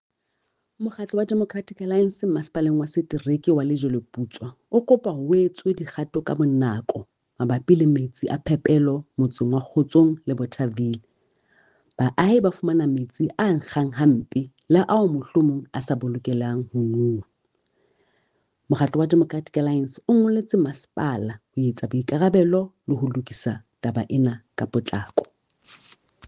Sesotho soundbites by Cllr Mahalia Kose and